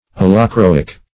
Allochroic \Al`lo*chro"ic\